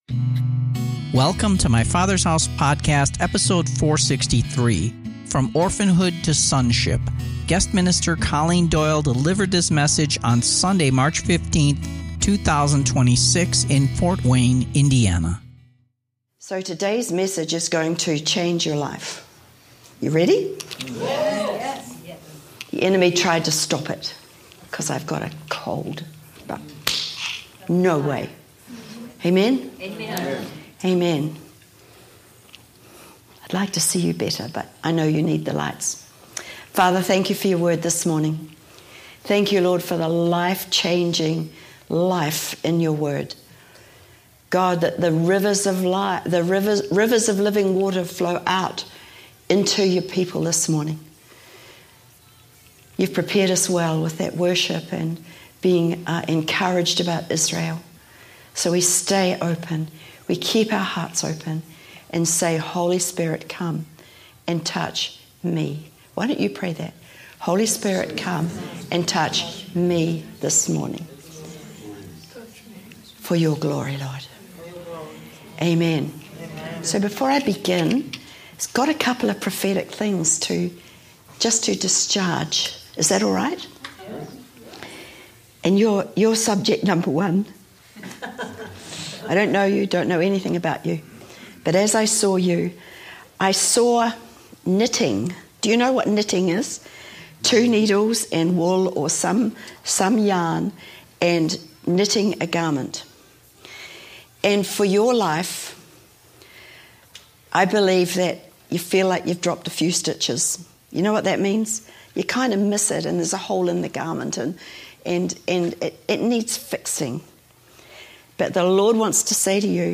Guest Minister